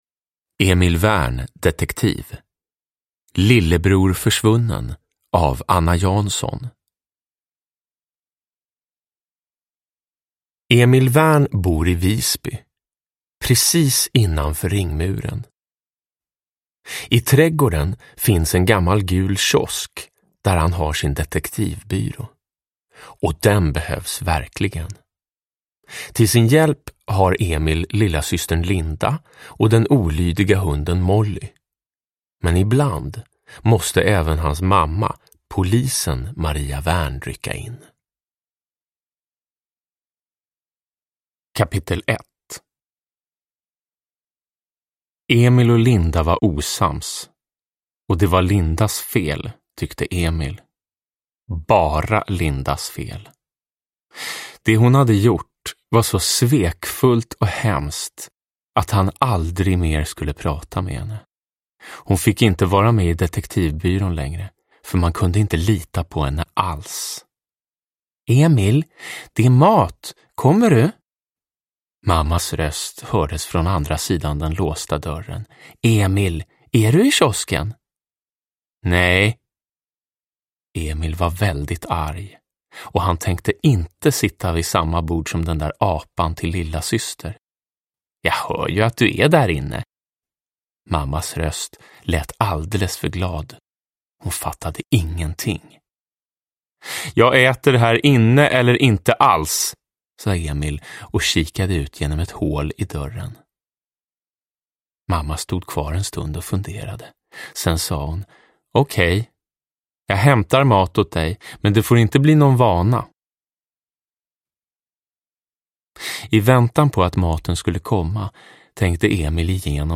Lillebror försvunnen – Ljudbok – Laddas ner
Uppläsare: Jonas Karlsson